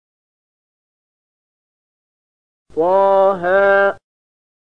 020001 Surat Thaahaa ayat 1 dengan bacaan murattal ayat oleh Syaikh Mahmud Khalilil Hushariy: